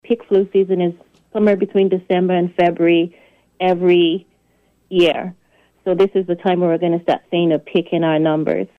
As we get deeper into the holiday and new year season, we are also quickly approaching the peak flu season, according to an illness trend update by Lyon County Health Officer Dr. Ladun Oyenuga on KVOE’s Morning Show Monday.